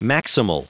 Prononciation du mot maximal en anglais (fichier audio)